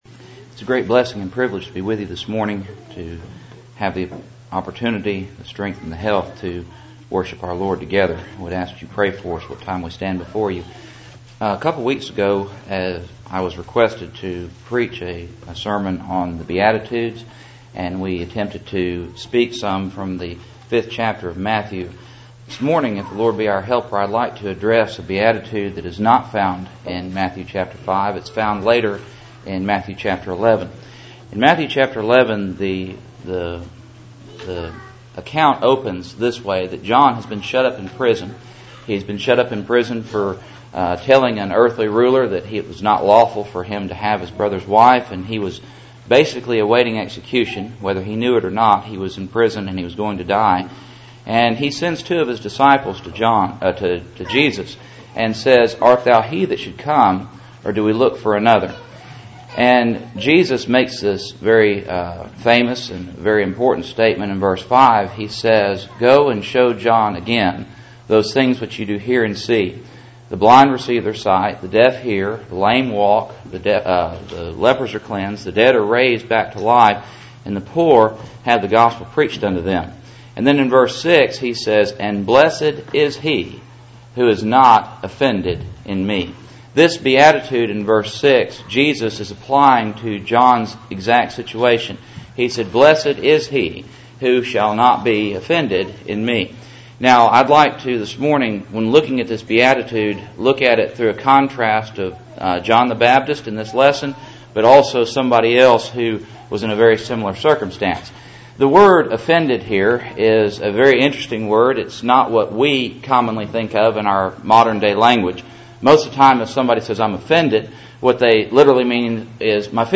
Matthew 11:6 Service Type: Cool Springs PBC Sunday Morning %todo_render% « Grace and Works Absalom’s Rise